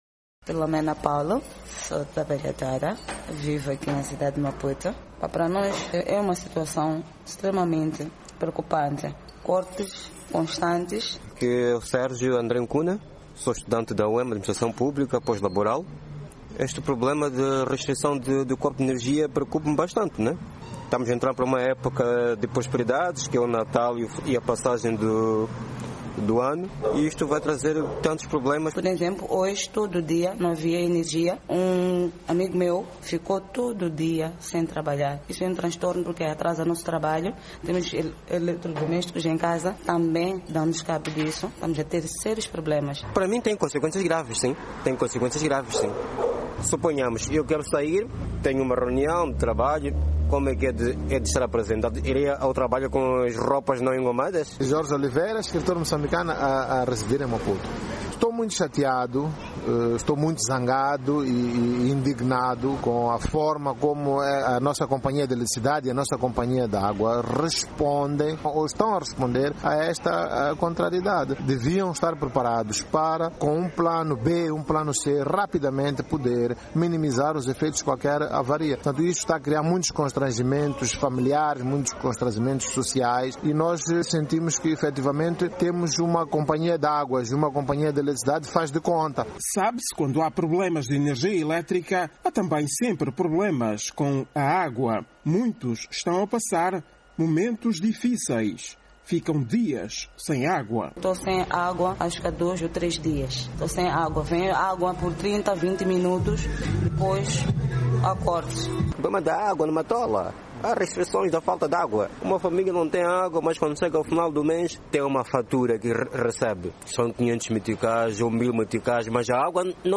Nas ruas, munícipes manifestaram à VOA a sua indignação e muita preocupação quando tomaram conhecimento que vão ter que sofrer restrições no fornecimento de energia elétrica durante mais 12 meses pelo facto de não ter sido possível resolver a avaria no transformador da Subestação da EDM, localizada na cidade da Matola, no sul de Moçambique.
Residentes de Maputo manifestam desapontamento por causa dos cortes de energia 1:30